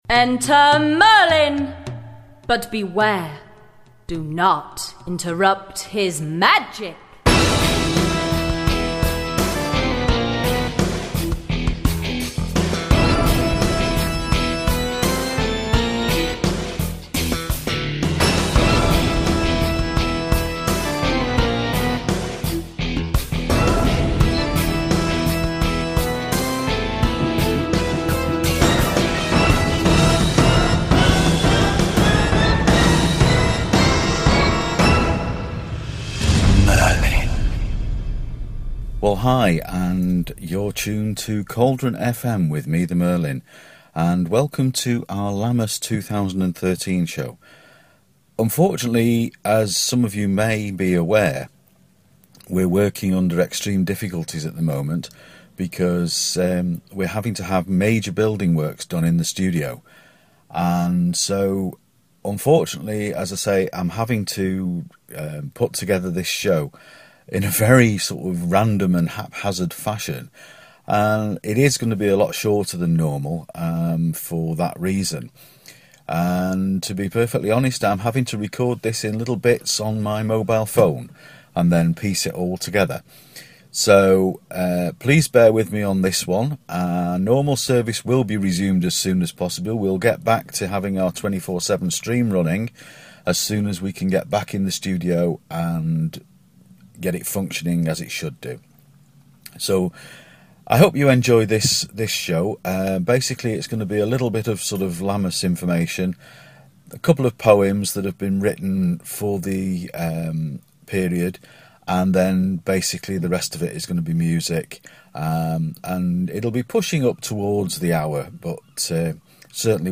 Despite the studio problems of late we have got a show together for you, featuring Lammas information and a lot of music.